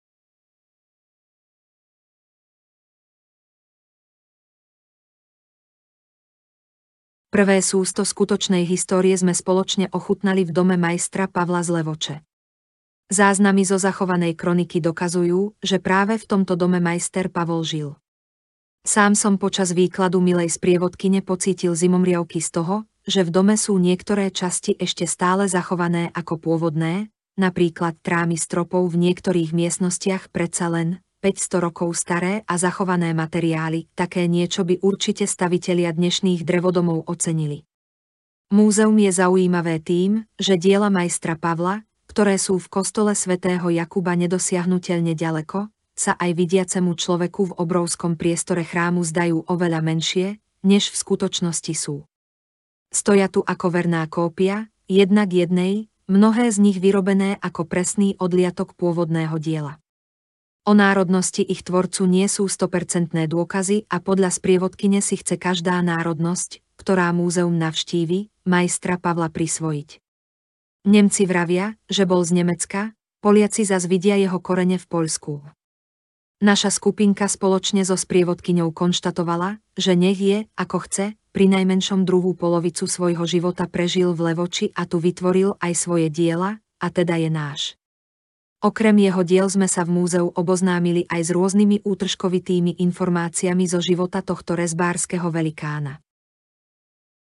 Načítavateľ 2: